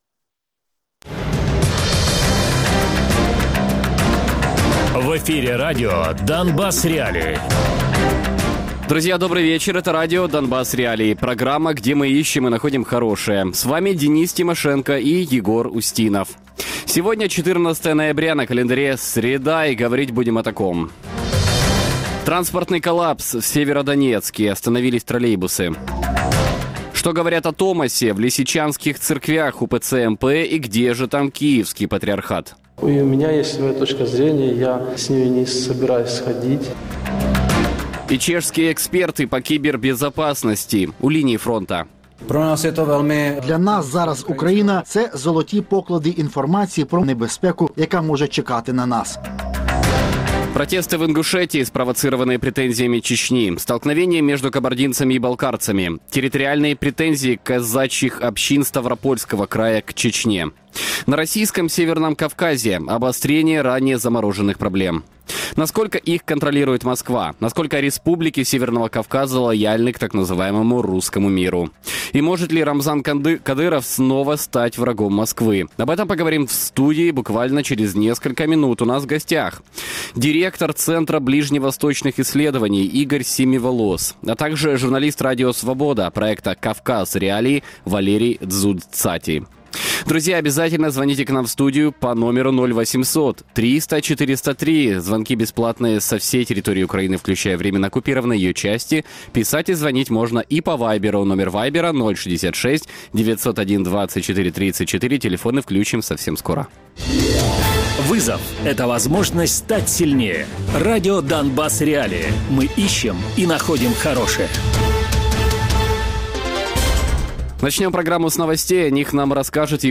проект «Кавказ.Реаліі» Радіопрограма «Донбас.Реалії» - у будні з 17:00 до 18:00.